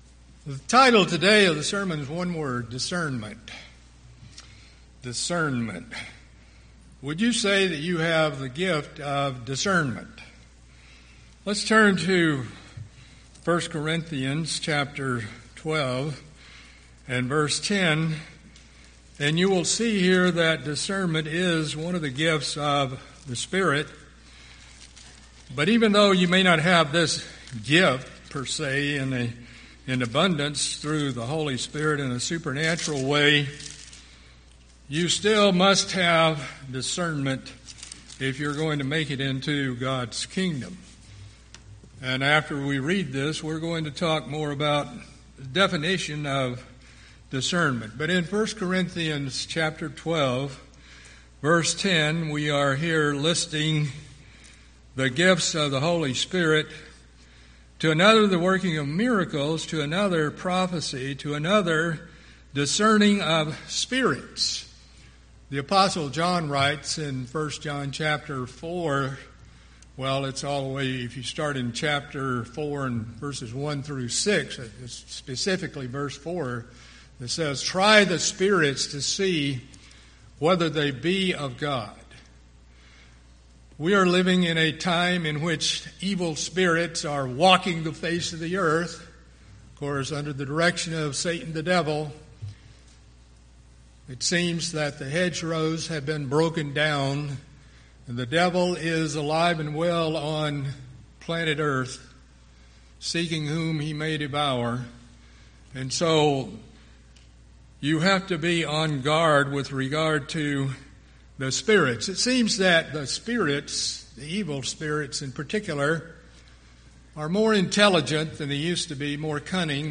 Discernment | United Church of God